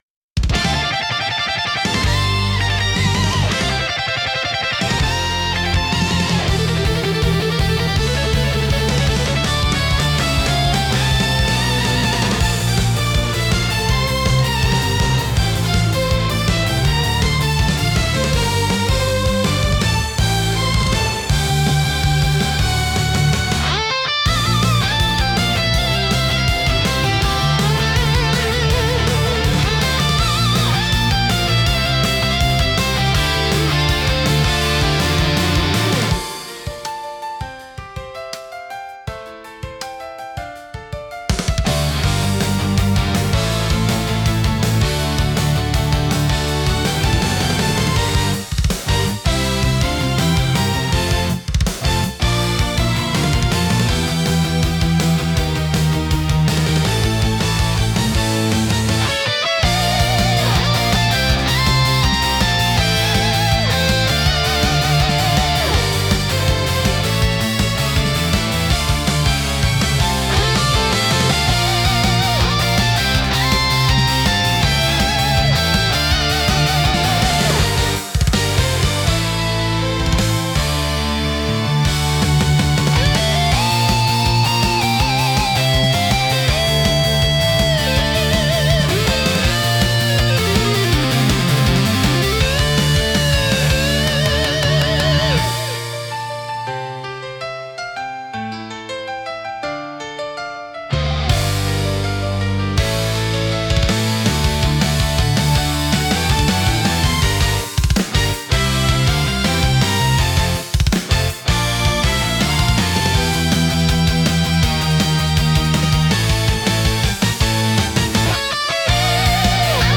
エネルギッシュかつ感動的なシーン演出に非常に向いているジャンルです。